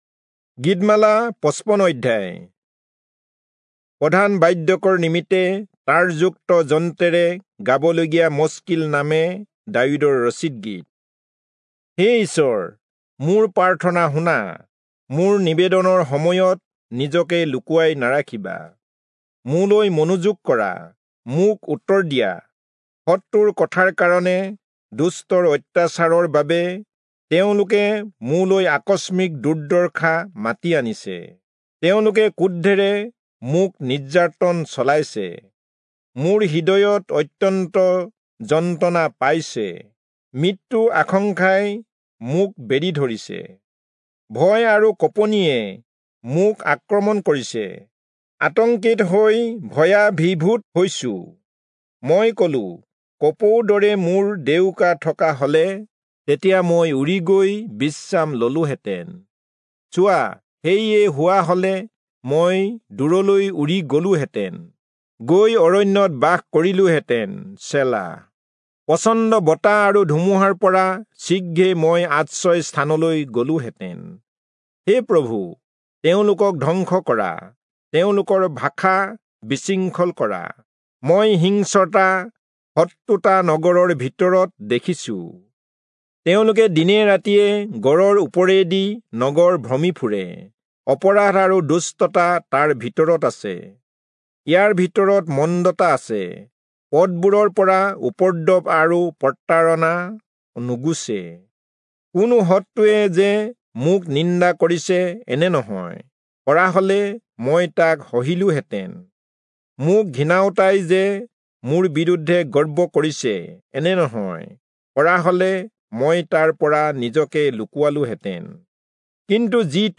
Assamese Audio Bible - Psalms 29 in Alep bible version